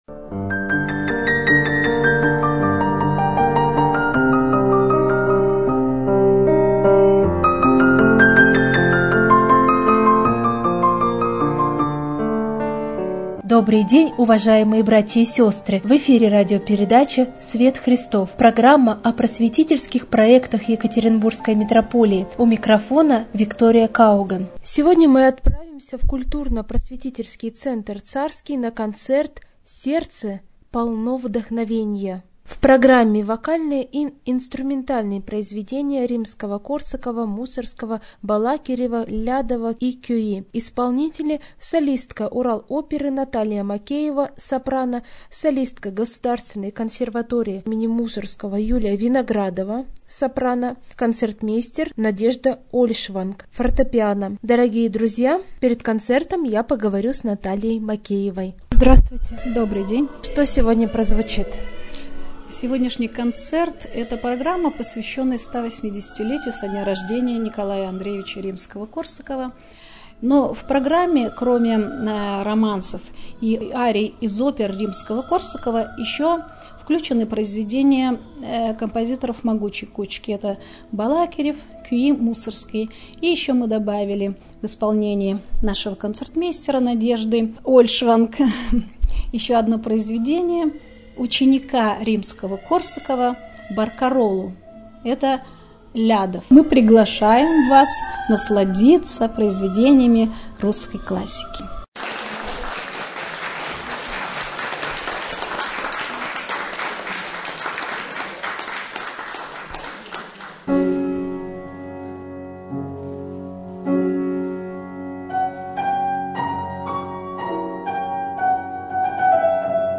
Концерт в центре Царский
koncert_v_centre_carskij.mp3